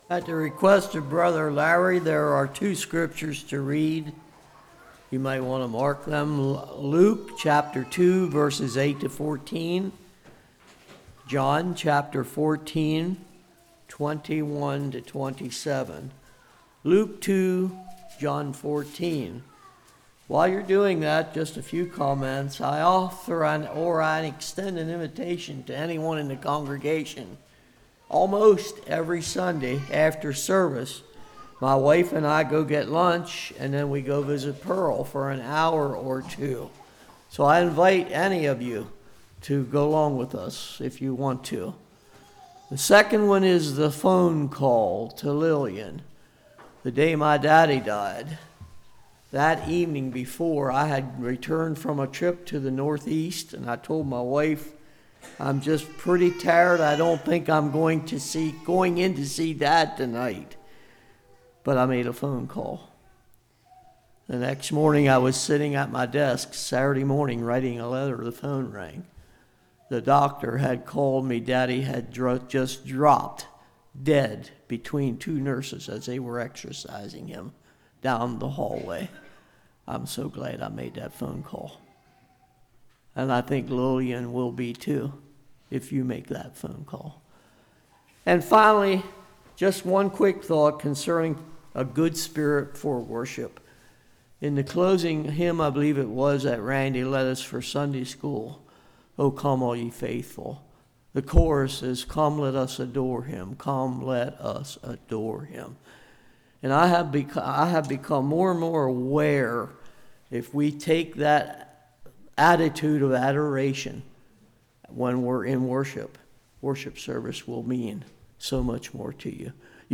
John 14:21-27 Service Type: Morning God of Peace Jesus is in Control Refrain from Evil Be a Peacemaker « Repentance Who Touched Me?